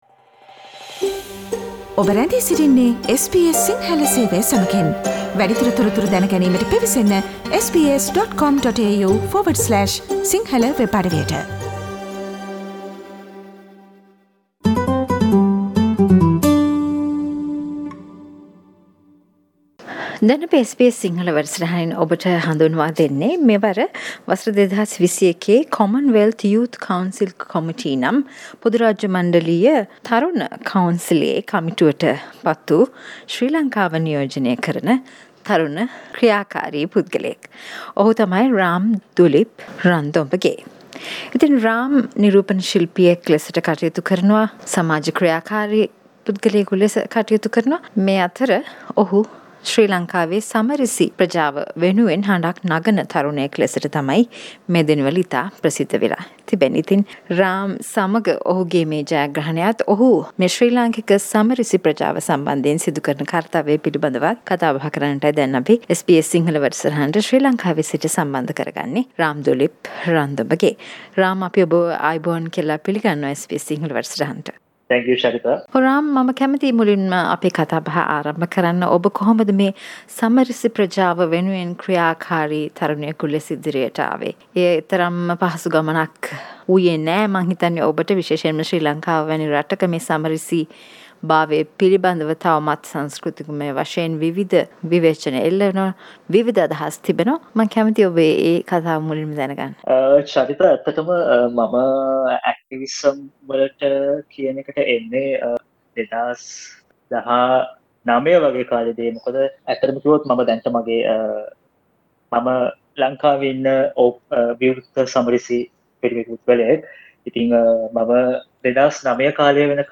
SBS සිංහල සේවය සිදු කල පිලිසදරට සවන් දෙන්න